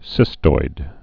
(sĭstoid)